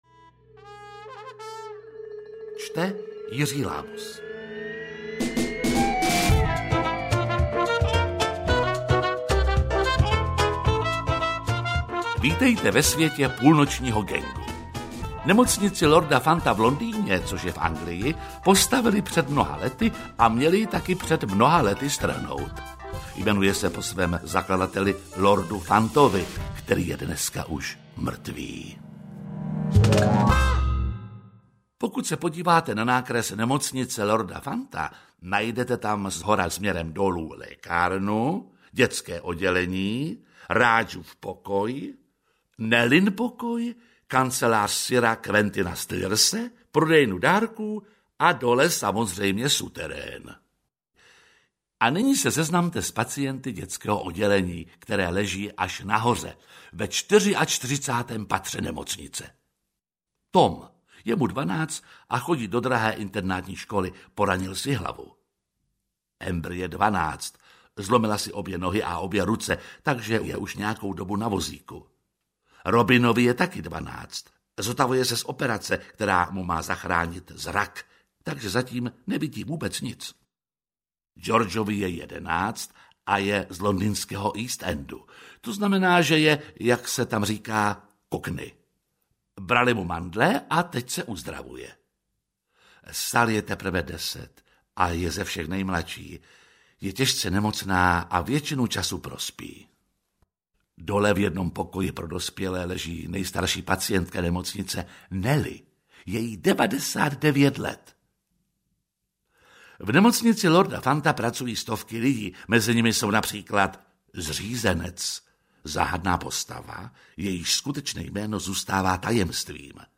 Půlnoční gang audiokniha
Ukázka z knihy
• InterpretJiří Lábus